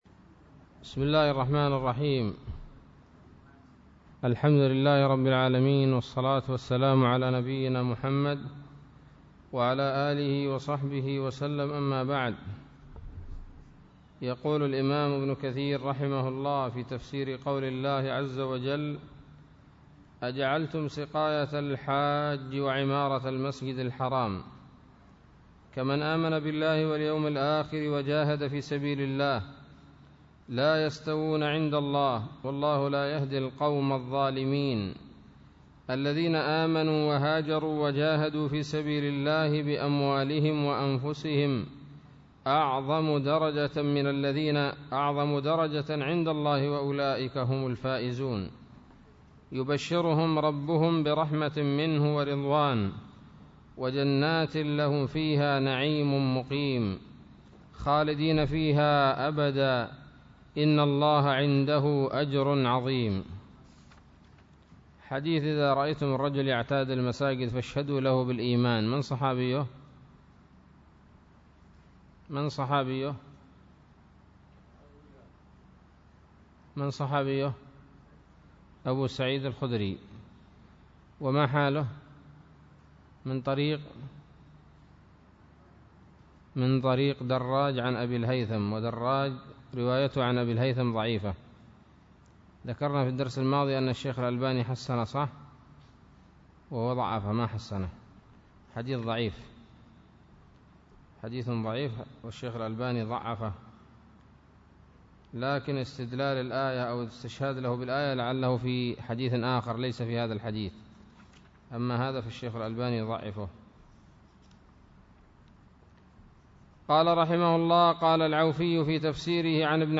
الدرس التاسع من سورة التوبة من تفسير ابن كثير رحمه الله تعالى